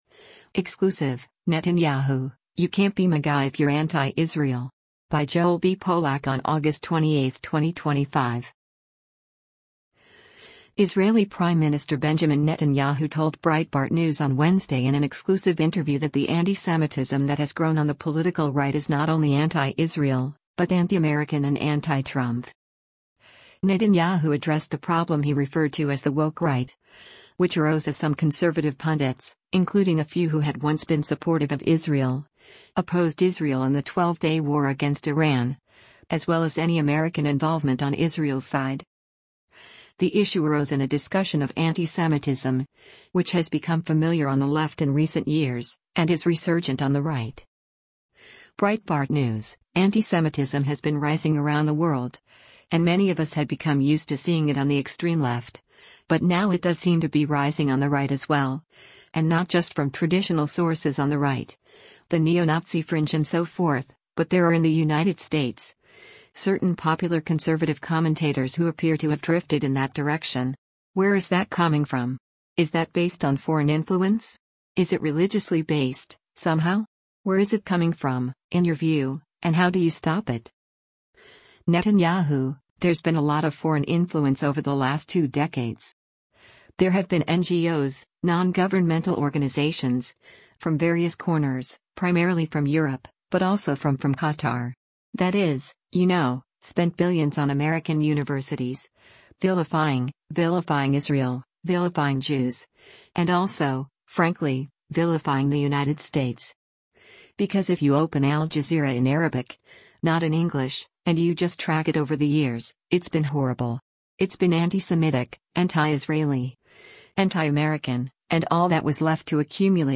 Israeli Prime Minister Benjamin Netanyahu told Breitbart News on Wednesday in an exclusive interview that the antisemitism that has grown on the political right is not only anti-Israel, but anti-American and anti-Trump.